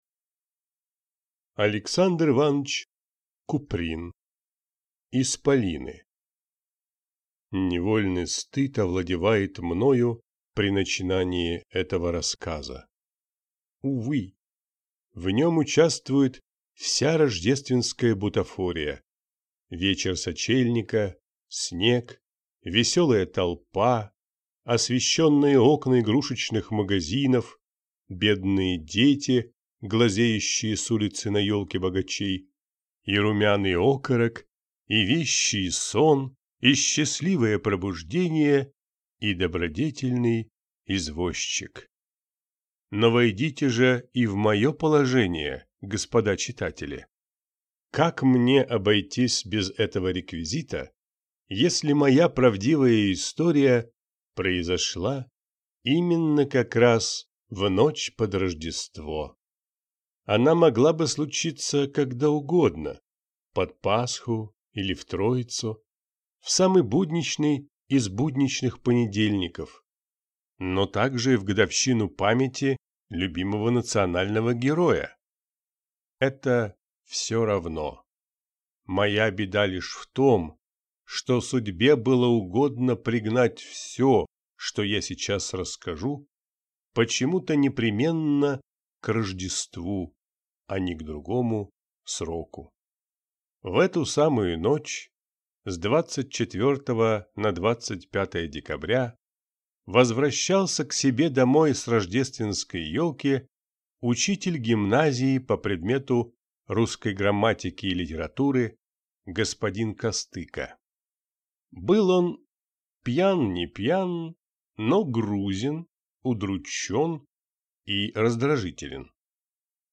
Aудиокнига Исполины